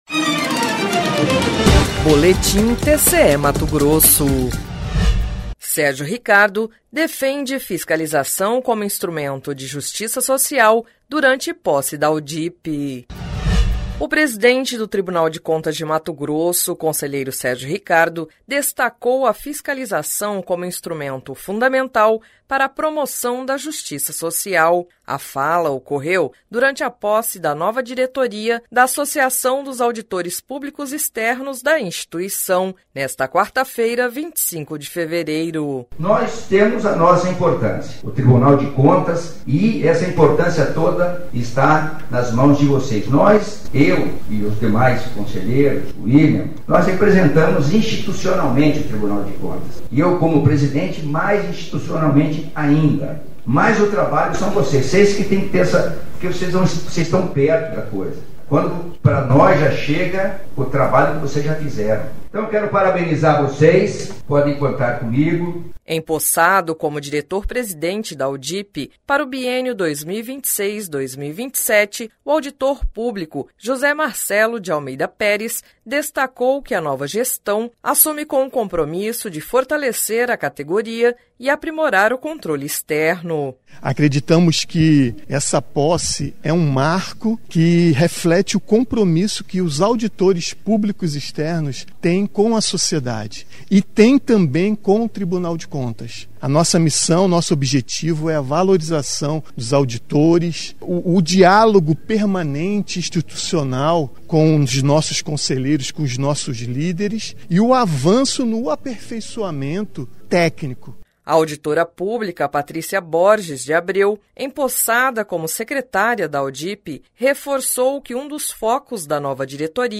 Sonora: Sérgio Ricardo – presidente conselheiro do TCE-MT
Sonora: William Brito Júnior - procurador-geral do MPC-MT